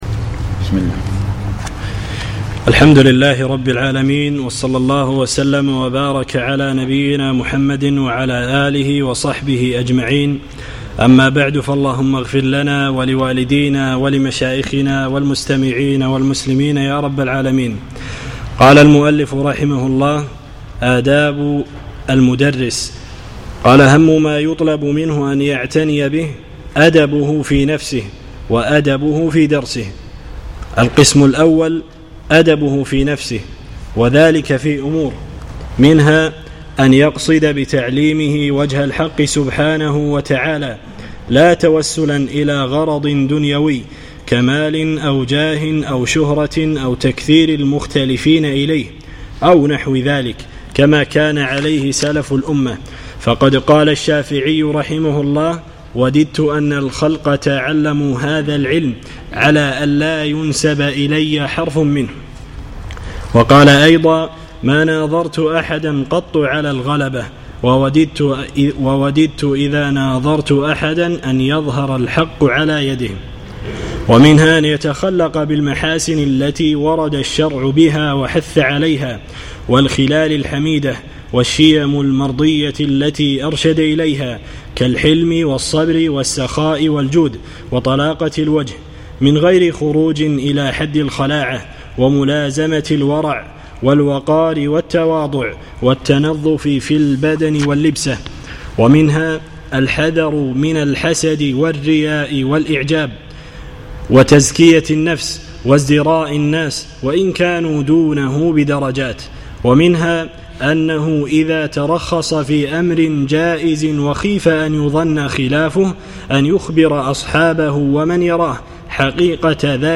الثلاثاء 21 جمادى الأولى 1437 الموافق 1 3 2016 مسجد الرويح الزهراء
الدرس الثاني